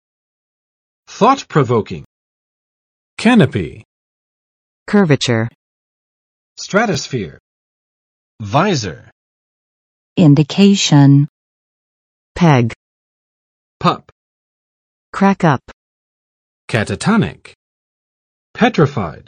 [ˋθɔtprə͵vokɪŋ] adj. 令人深思的，引起思考的
thought-provoking.mp3